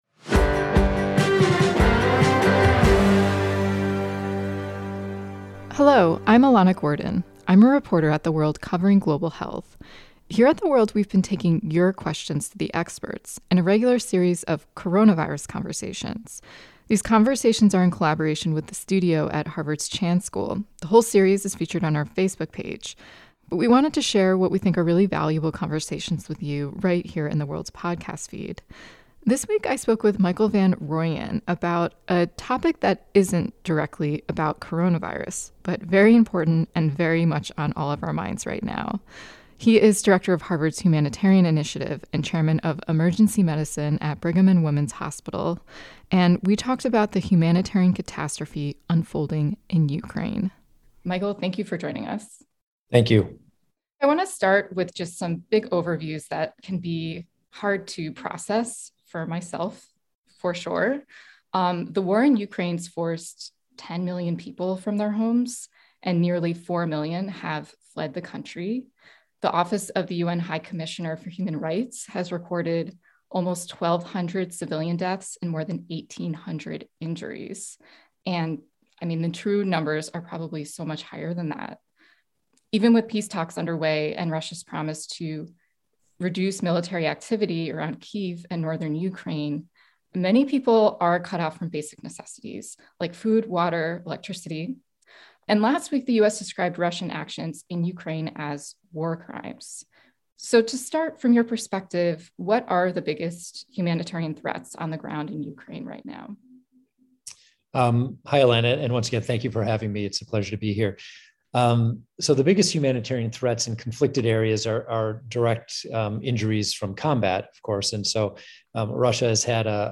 moderated a discussion